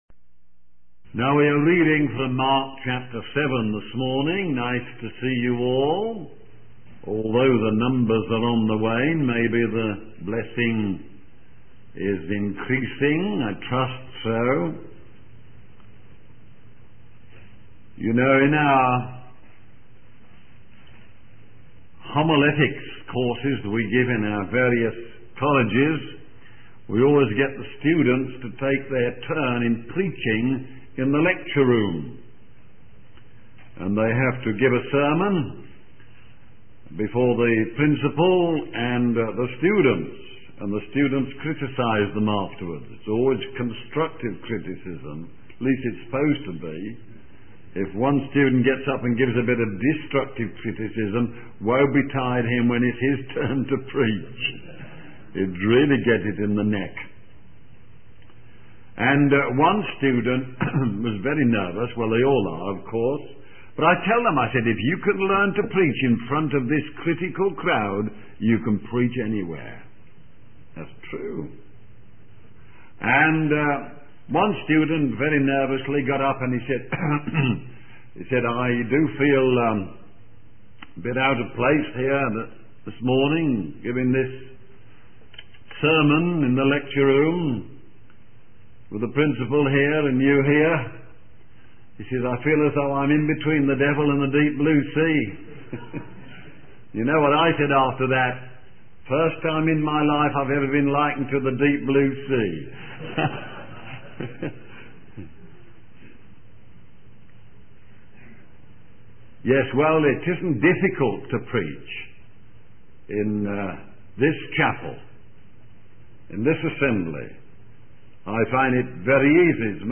In this sermon, the preacher emphasizes that Jesus did everything well and brought blessings wherever he went. He highlights how Jesus helped the prisoner, brought rest to the weary family, and blessed those in need. The preacher also discusses how Jesus exposed the traditionalist and hypocrite, while uplifting the humble and healing the deaf and mute.